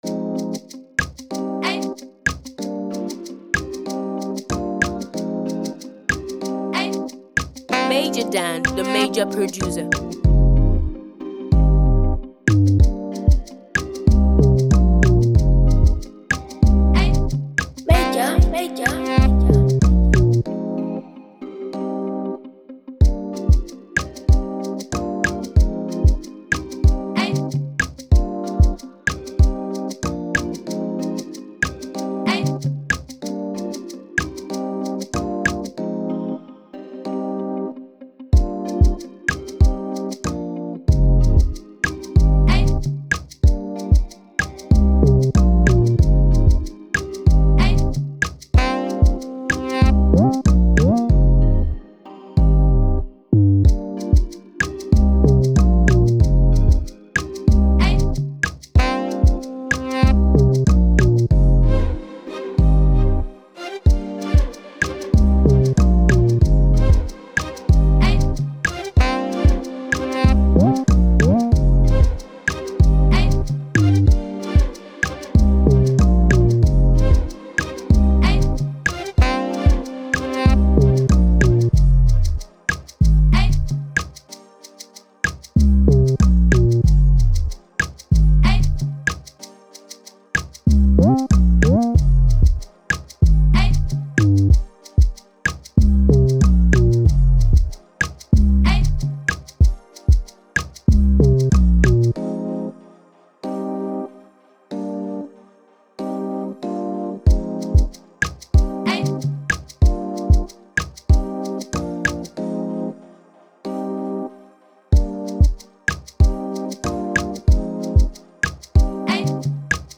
with rich African rhythms and modern flair